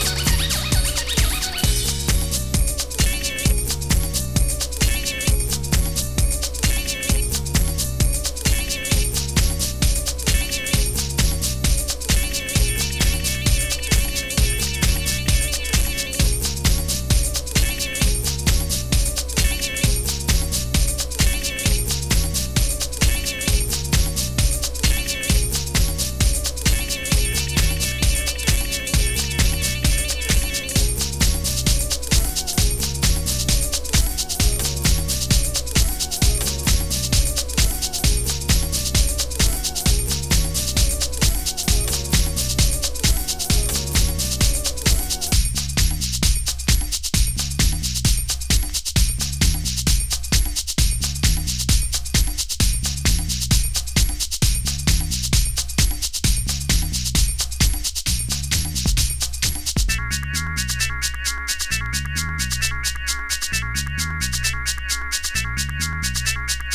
ファットなビート、程よいジャジーさ、デトロイティッシュなシンセフレーズetc。飽きの来ないサウンド。